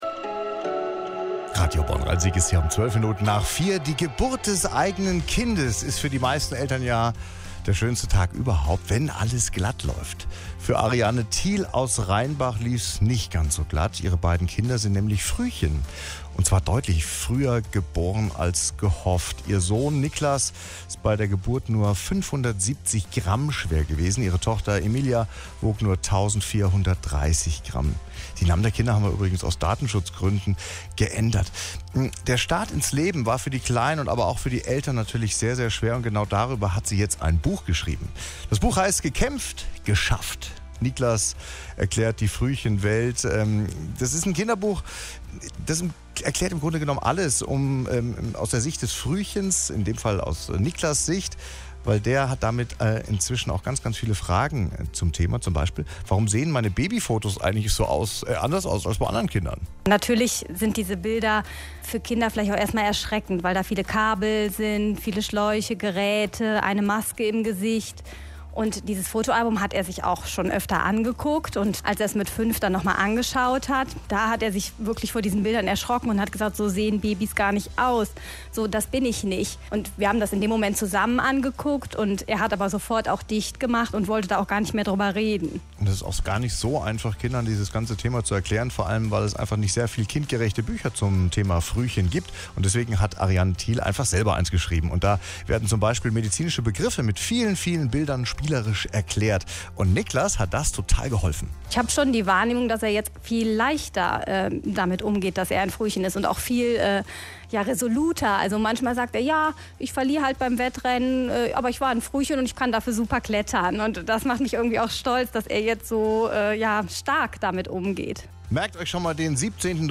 Mit Genehmigung von Radio Bonn / Rhein-Sieg Info und Interview aus